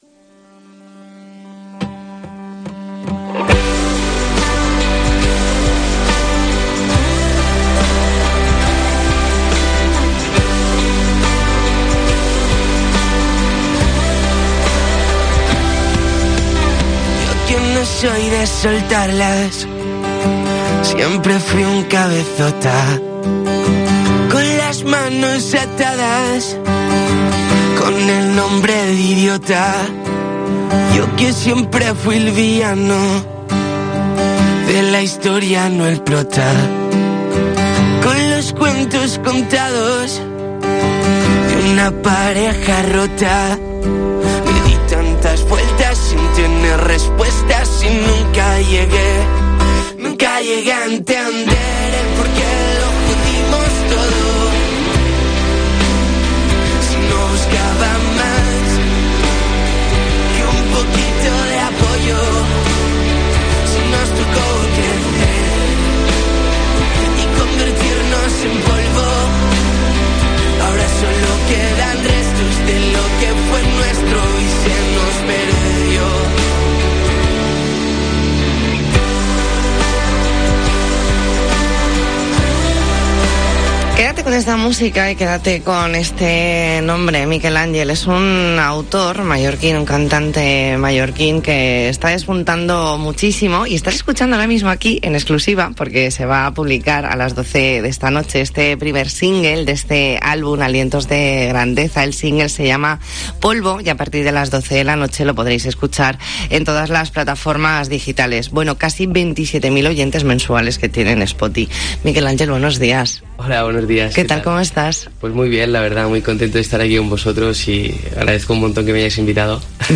E ntrevista en La Mañana en COPE Más Mallorca, jueves 5 de mayo de 2022.